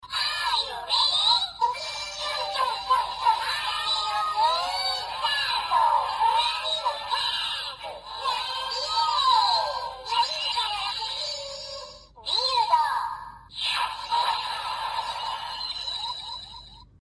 Build饱藏音效.MP3